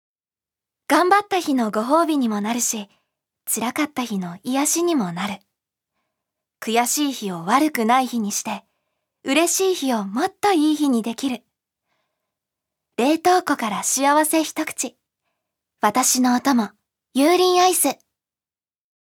預かり：女性
ナレーション３